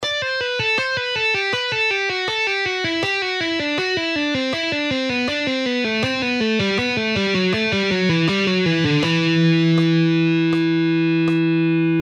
Cascading style guitar licks
Lesson 2: Legato Exercise In Cascade – Upper Harmony
In half speed:
6.-Legato-Exercise-In-Cascade-Upper-Harmony-Half-Speed.mp3